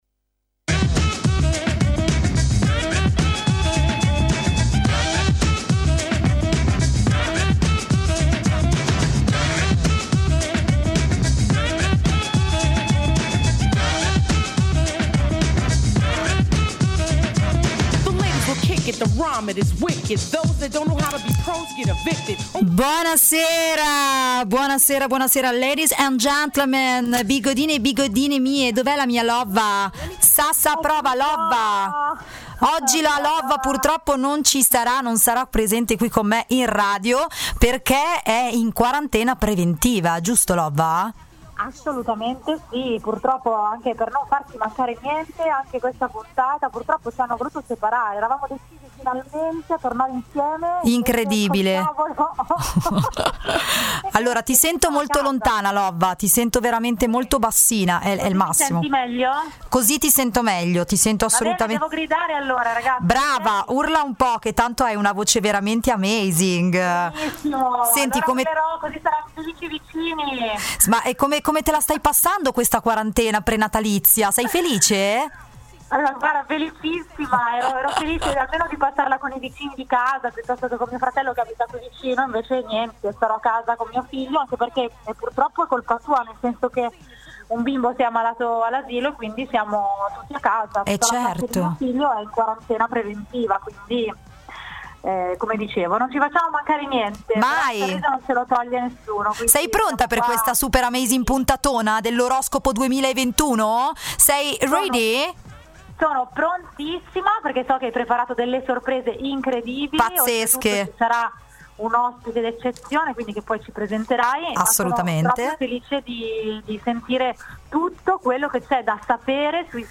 Tutto lo zodiaco, inclusi i pianeti in transito, le costellazioni, pregi e difetti di tutti i segni, il tutto sempre in diretta live con le nostre voci!!!